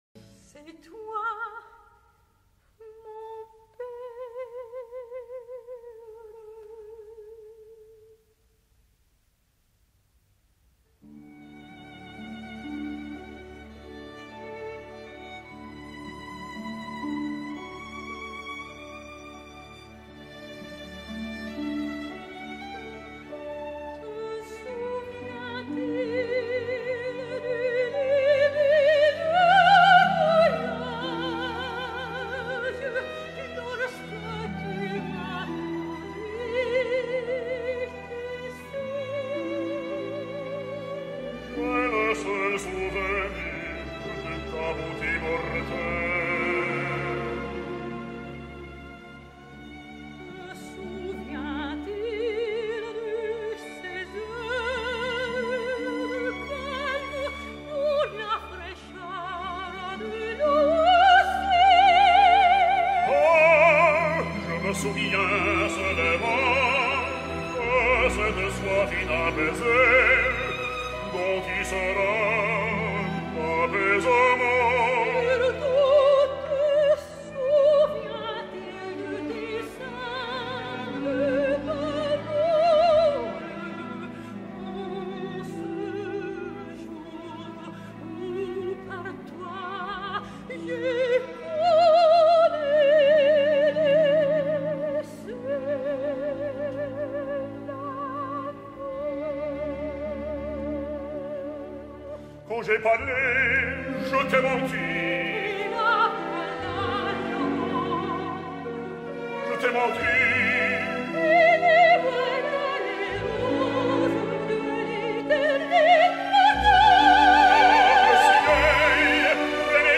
on va deixar mostres del seu estil elegant
Aquí els teniu cantant l’inspirat duo final